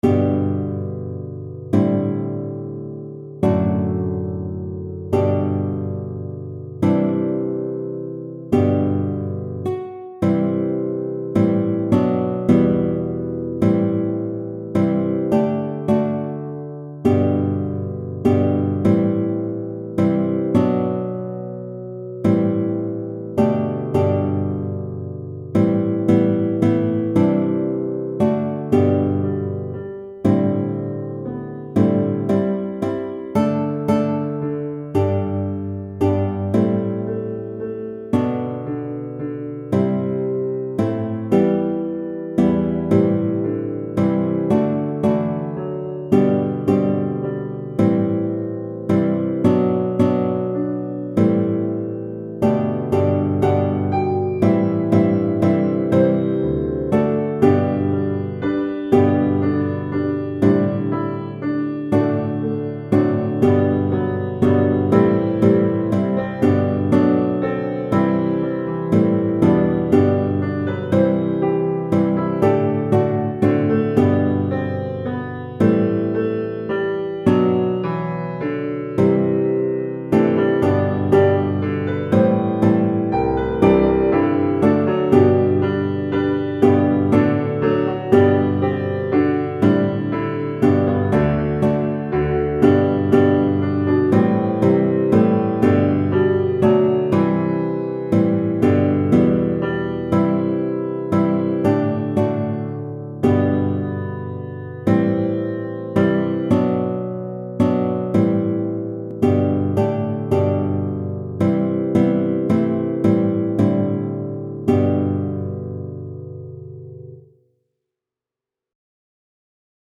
Guitar and Piano Accompaniment: